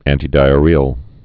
(ăntē-dīə-rēəl, ăntī-)